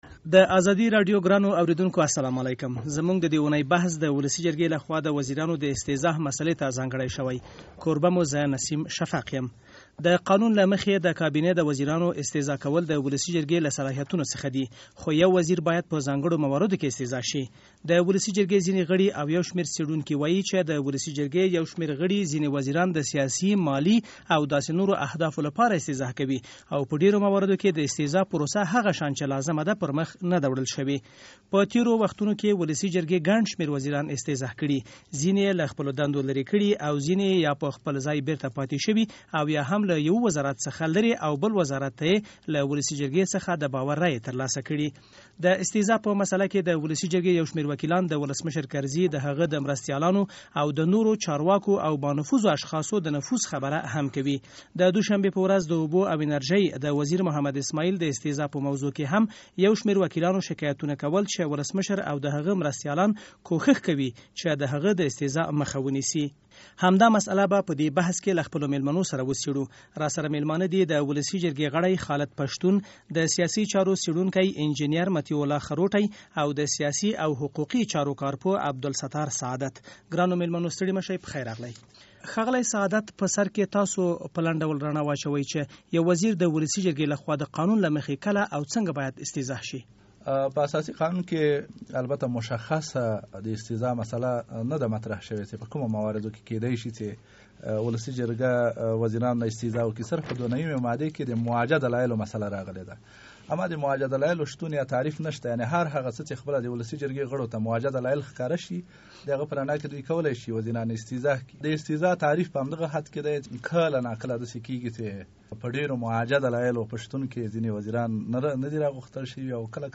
د ازادۍ راډیو د اوونۍ بحث